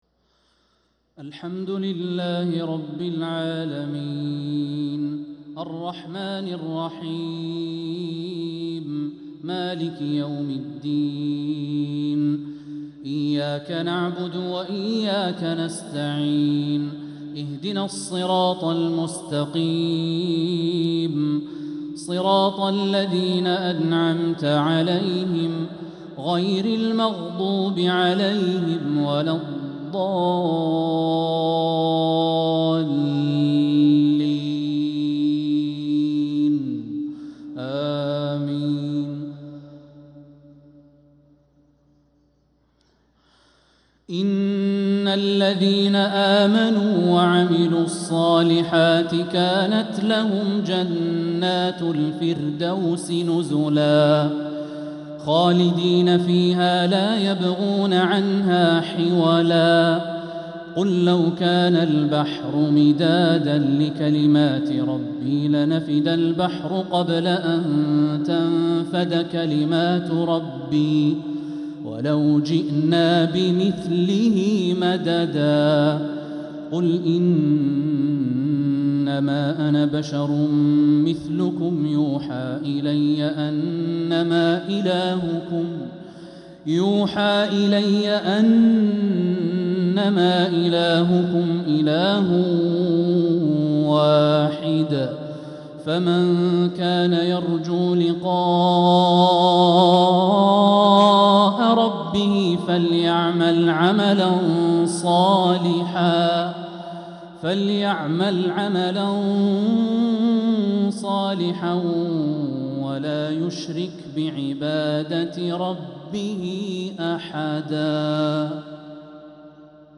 مغرب الخميس 2-7-1446هـ خواتيم سورتي الكهف 107-110 و مريم 96-98 | Maghrib prayer from Surat al-Kahf & Maryam 2-1-2025 > 1446 🕋 > الفروض - تلاوات الحرمين